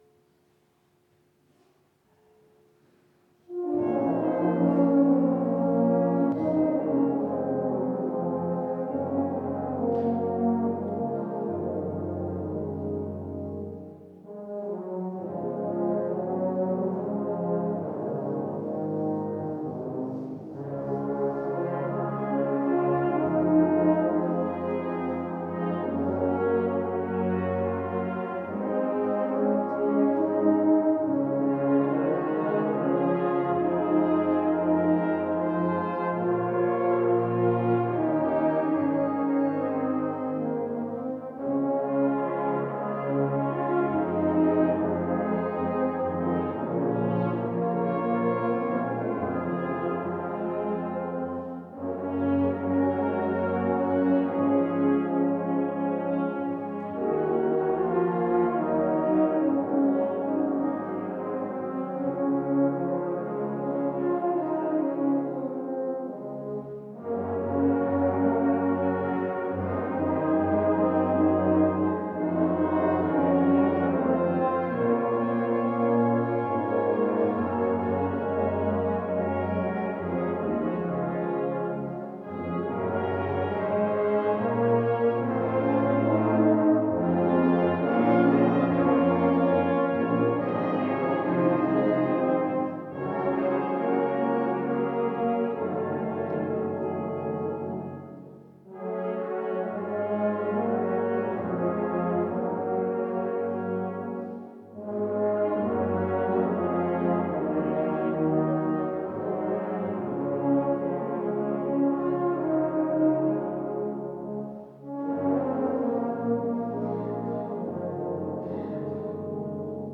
Bläsereingang Bläserchor der Ev.-Luth. St. Johannesgemeinde Zwickau-Planitz 4:42
Audiomitschnitt unseres Gottesdienstes zum Epipaniasfest 2026.